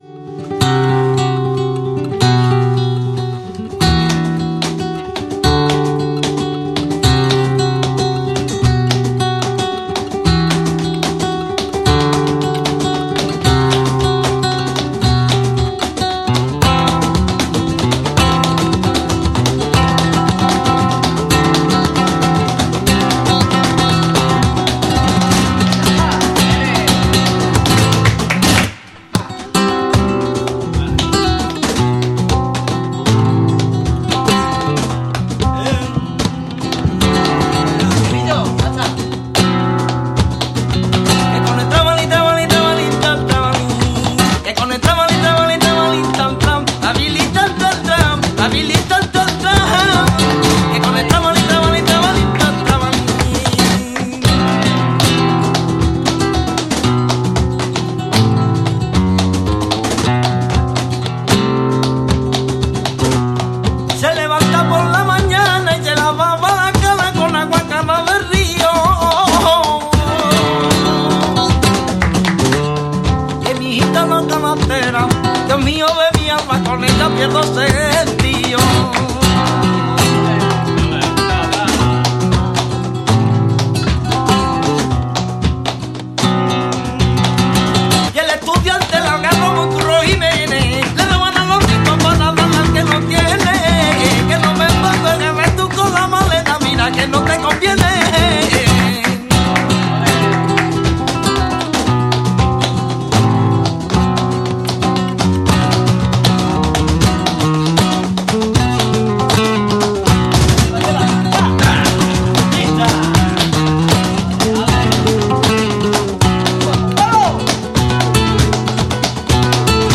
Kontzertuaren aurretik gurera etorri dira eta aurrerapen eder bat eskaini digute. Dudarik gabe, Txapa Irratian izan dugun Jam sessionik zirraragarriena!!
berbenir-flamenko.mp3